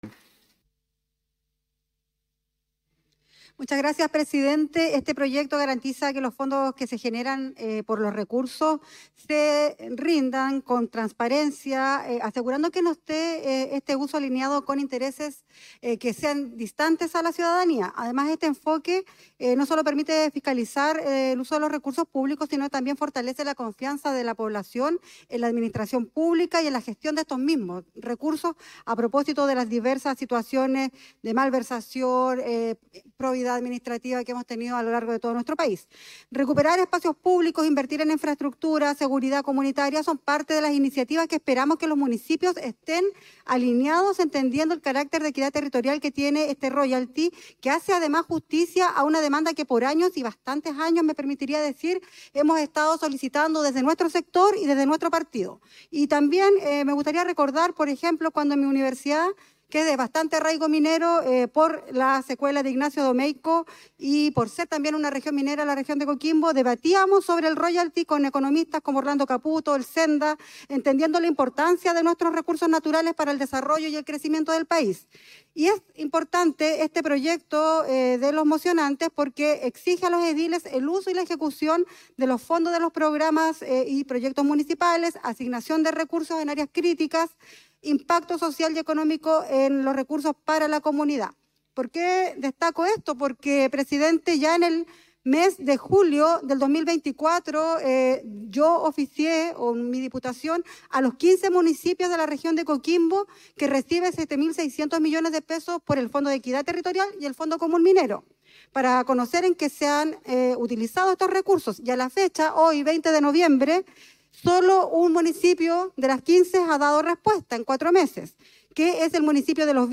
Intervencion-en-audio-de-la-diputada-Castillo.mp3